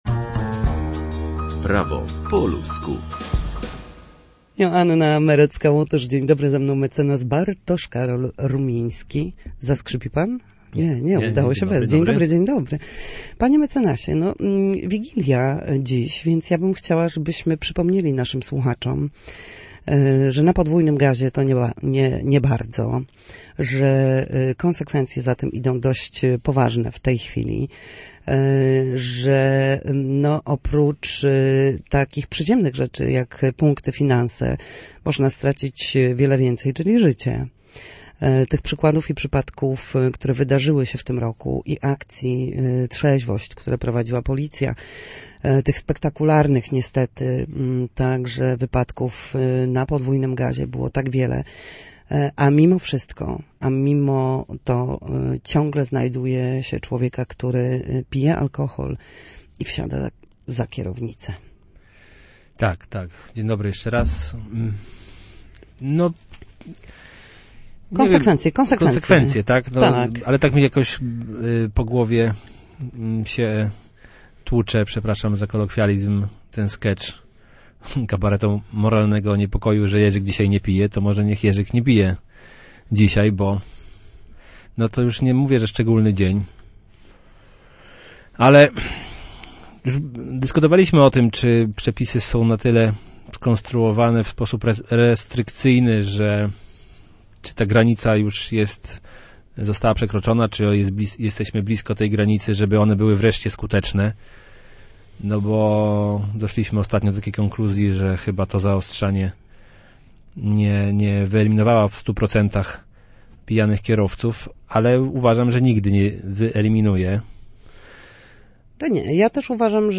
W każdy wtorek o godzinie 13:40 na antenie Studia Słupsk przybliżamy państwu meandry prawa. Nasi goście, prawnicy, odpowiadają na pytania dotyczące zachowania w sądzie czy podstawowych zagadnień prawniczych.